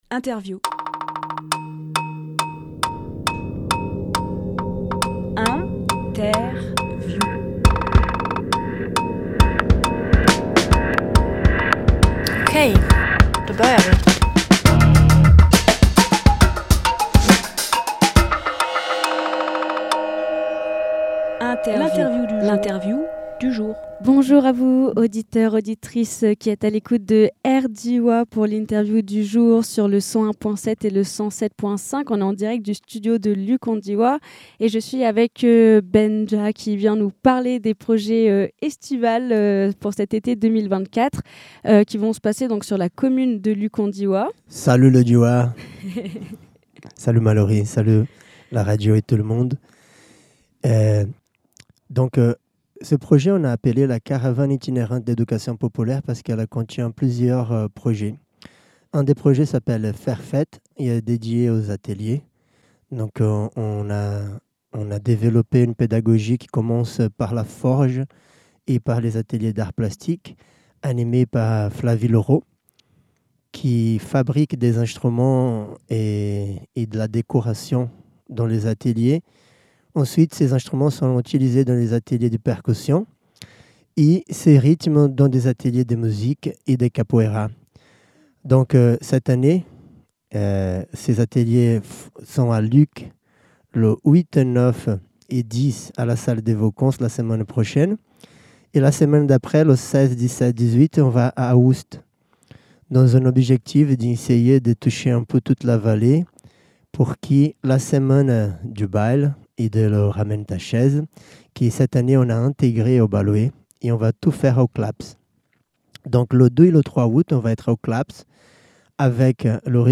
Emission - Interview Faire Fête Publié le 6 juillet 2024 Partager sur…